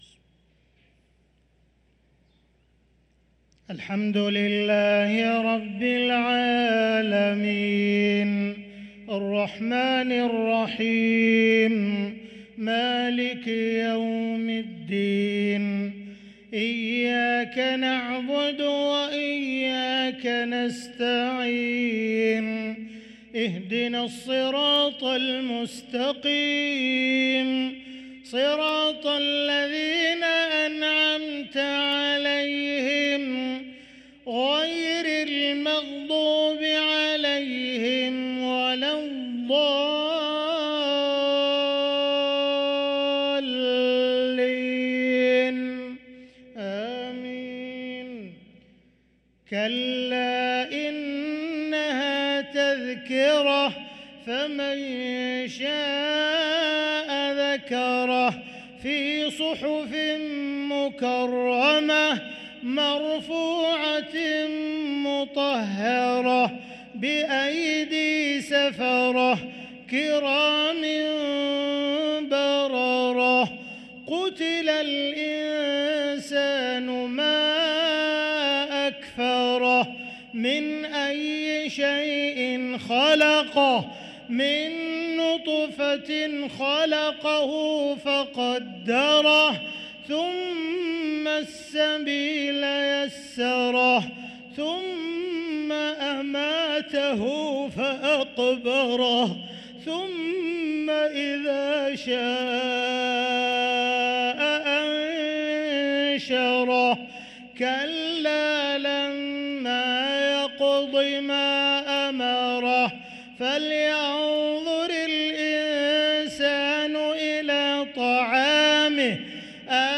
صلاة العشاء للقارئ عبدالرحمن السديس 15 ربيع الأول 1445 هـ
تِلَاوَات الْحَرَمَيْن .